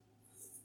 wool_1.mp3